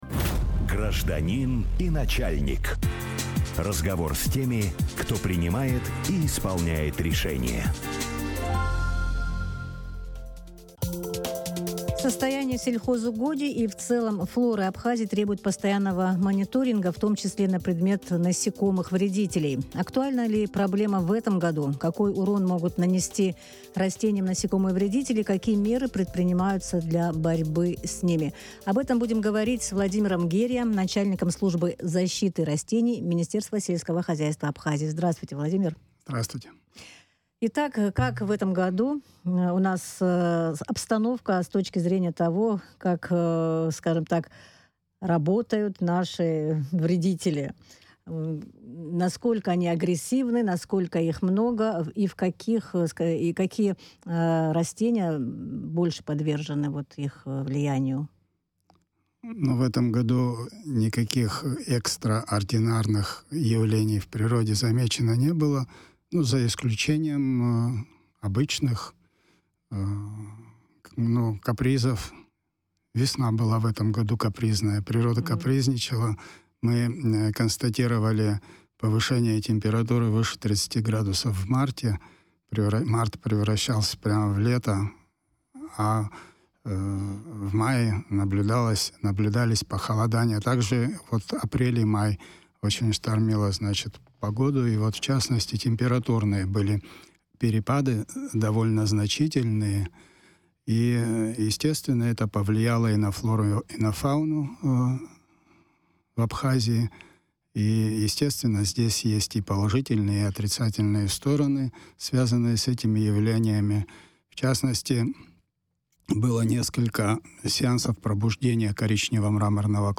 Начальник службы защиты растений Минсельхоза Абхазии Владимир Герия в эфире радио Sputnik рассказал о ситуации с распространением вредителей растений и борьбе с ними.